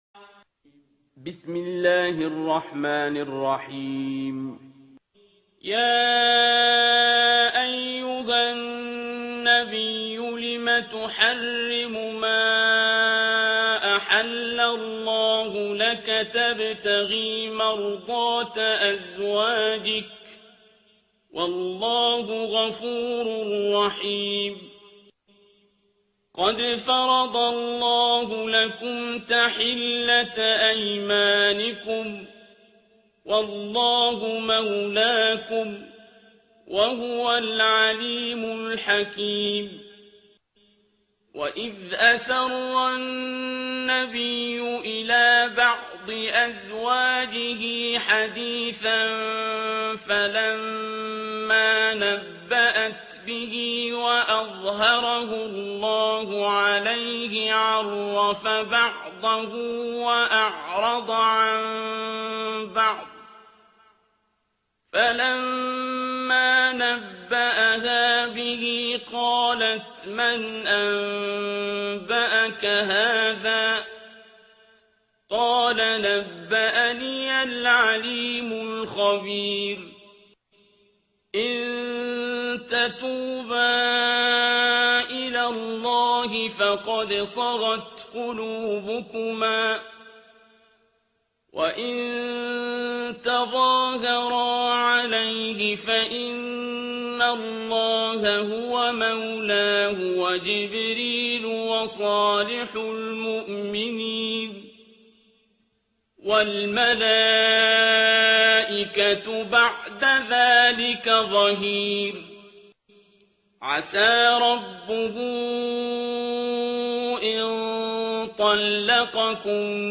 ترتیل سوره تحریم با صدای عبدالباسط عبدالصمد
066-Abdul-Basit-Surah-At-Tahrim.mp3